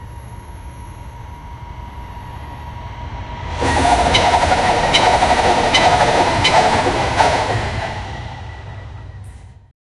Free AI Sound Effect Generator
train-on-tracks-fade-in-mxejy33f.wav